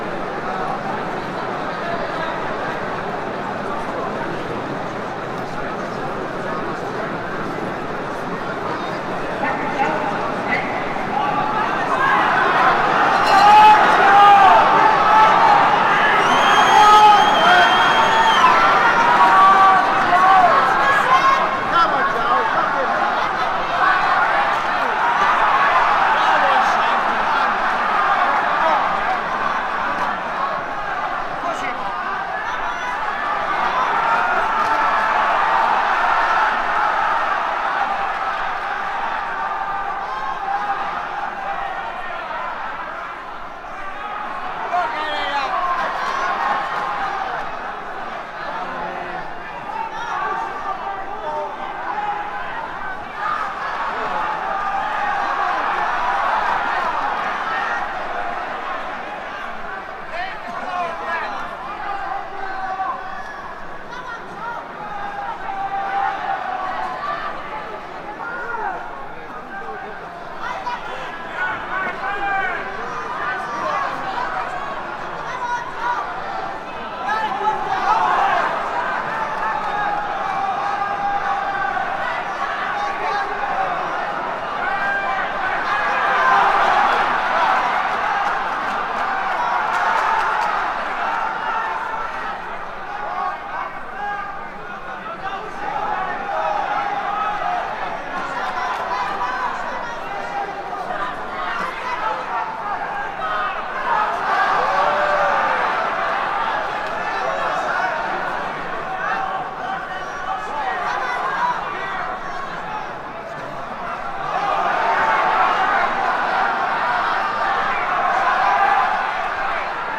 fight-yells-2.ogg